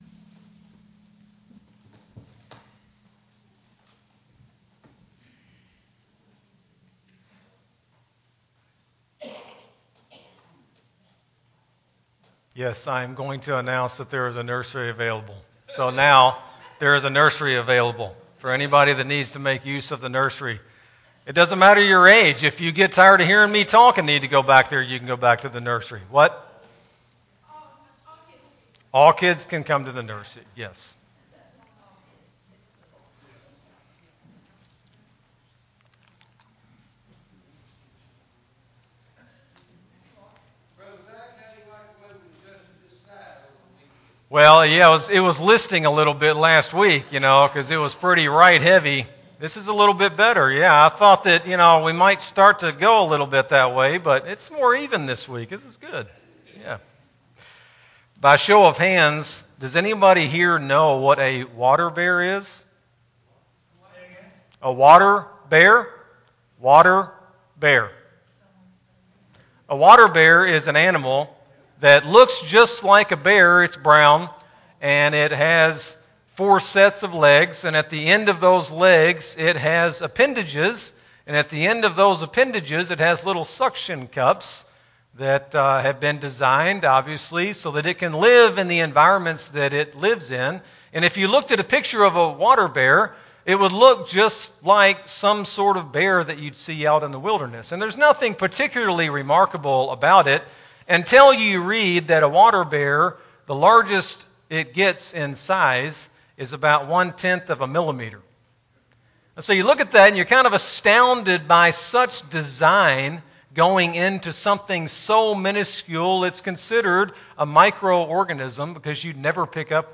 Sermon-5-14-23-CD.mp3